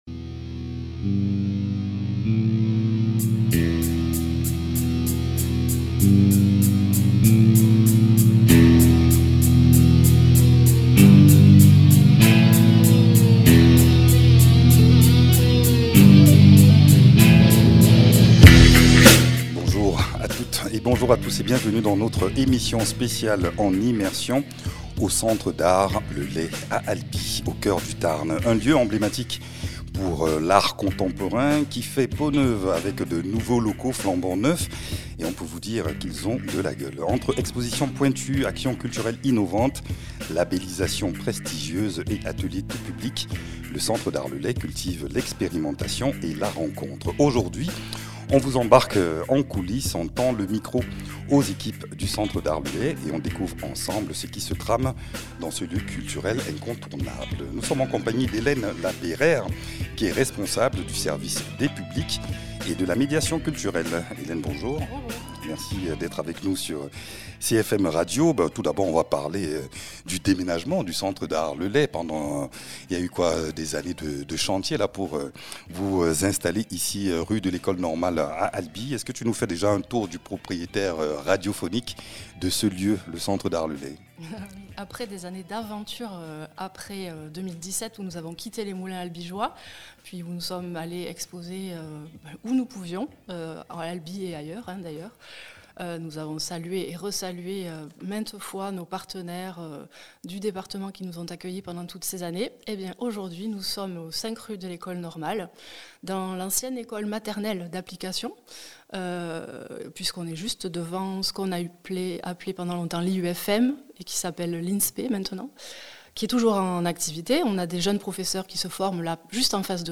Dans cette émission enregistrée sur place, on parle du lieu et de ses possibilités, des projets artistiques et de médiation, ainsi que des liens au territoire et de l’accueil de tous les publics.
Interviews